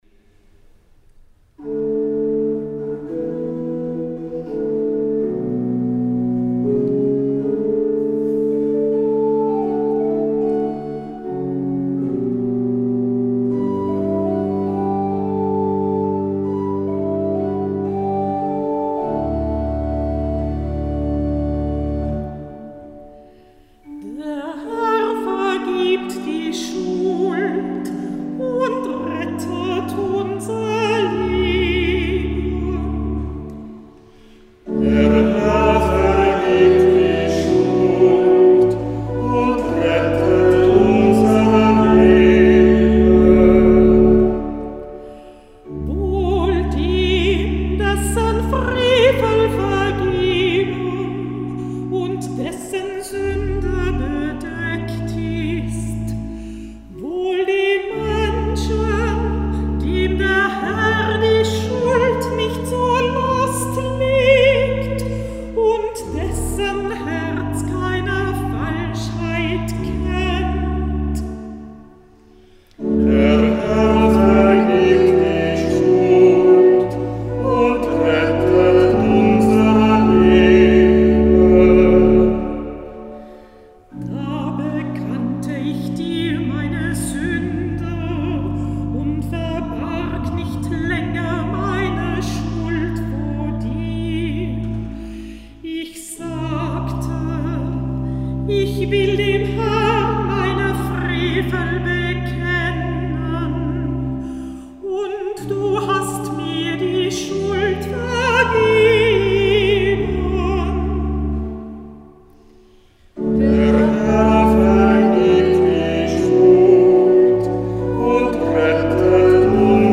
Hörbeispiele aus Kantorenbüchern und eine Auswahl aus dem Gurker Psalter
Hörbeispiele: Kehrverse im neuen Gotteslob
Ein- und mehrstimmige Antwortpsalmen